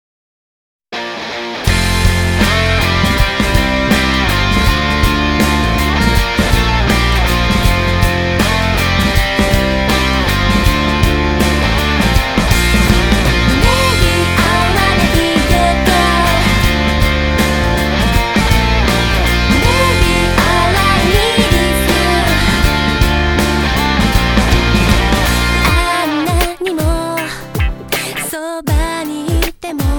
Vocal Pop